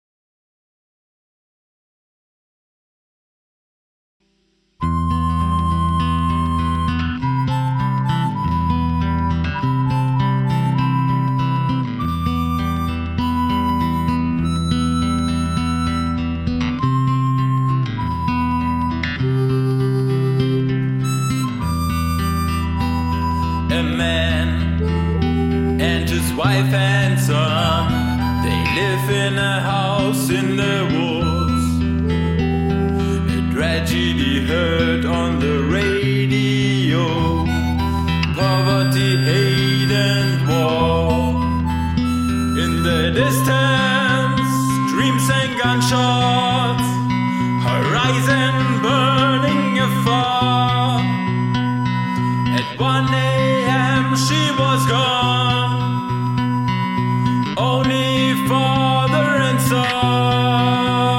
Harscher, schriller Sound beim Mundharmonika recorden - Bug oder Feature?
Er hat in das Kondensatormikrofon geblasen, das wir auch für Gesangsaufnahmen benutzen.
Was in unserem Home-Studio noch okay klang, war daheim beim Mixen plötzlich sehr schrill und unangenehm. Ich habe versucht, es ein wenig mit gedämpften Höhen und Tremolo zu retten... aber ich wundere mich doch sehr, ob das normal ist.
Beim Aufnehmen habe ich ein wenig bemerkt, dass es Störgeräusche gibt, wenn man zu stark hineinbläst.
Beim Gesamtsound habe ich es erst für eine Flöte gehalten.